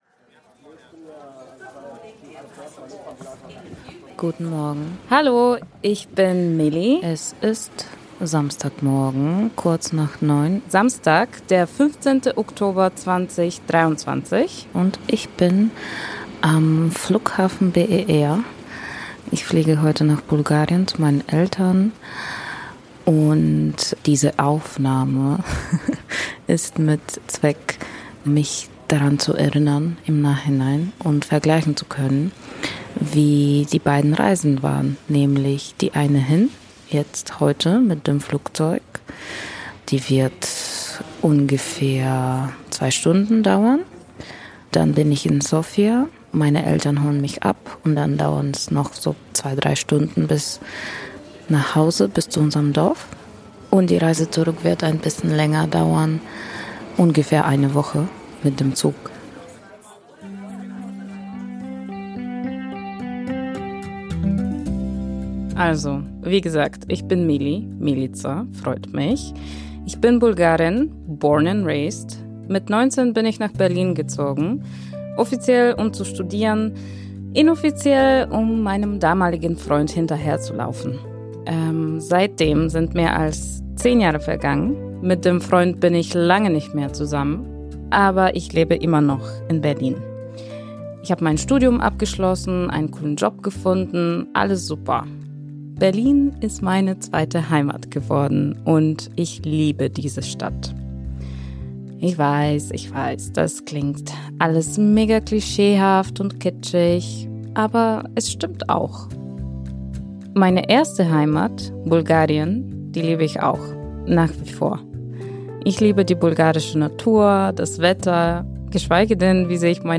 Und weil das viel zu harmonisch klingt (bis auf diesen einen nervigen Hund…), stellen wir uns noch ein paar Fragen: